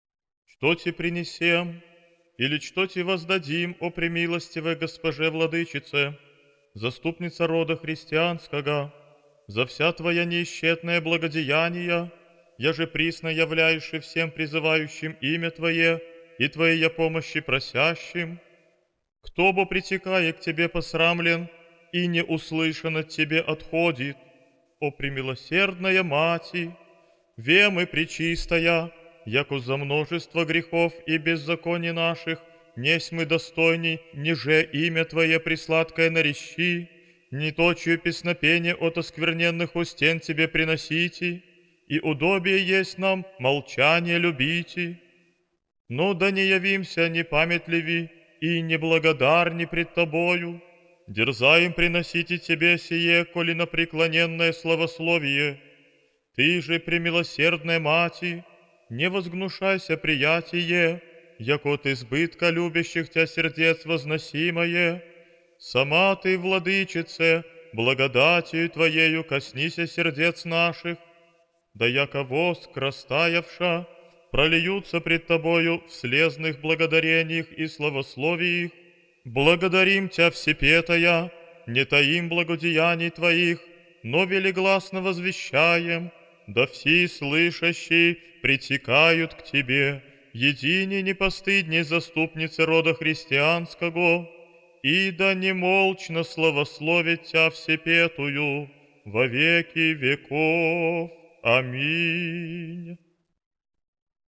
Аудио-молитва ко Пресвятой Богородице создает атмосферу благодарности и духовного утешения.
Аудиомолитва благодарственная Пресвятой Богородице